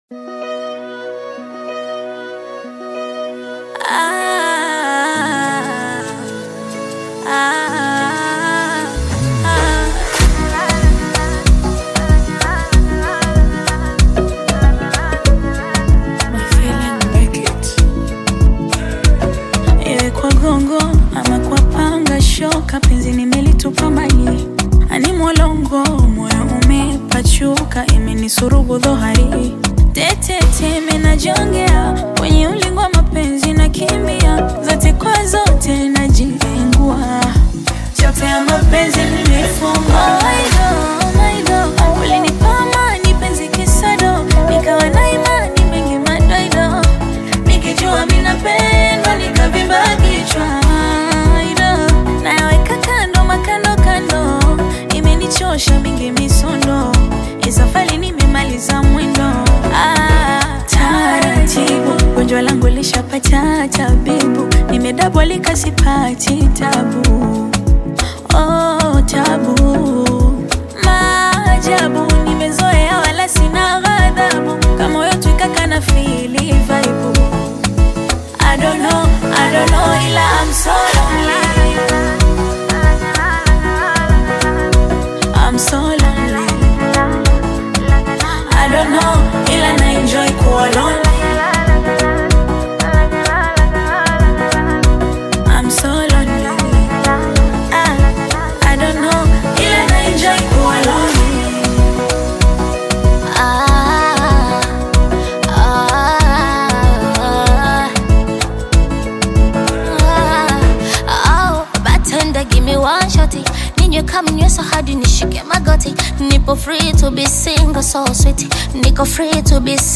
female recording artist, singer, and songwriter
Bongo Fleva